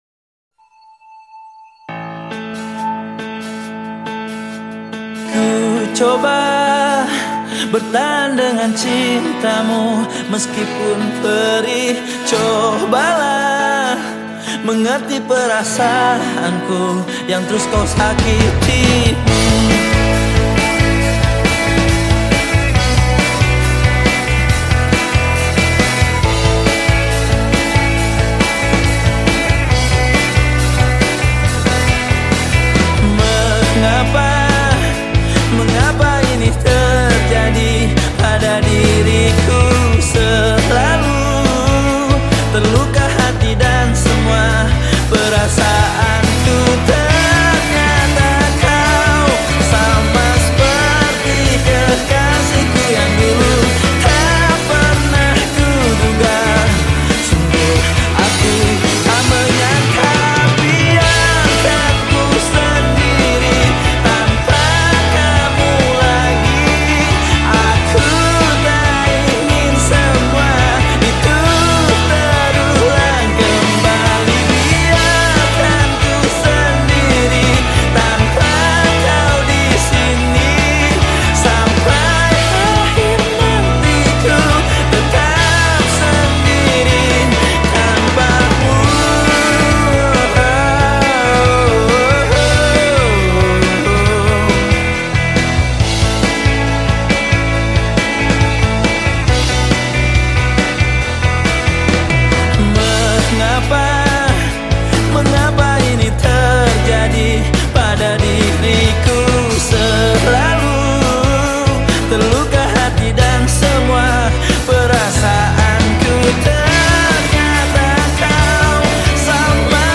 Genre Musik                     : Rock Alternatif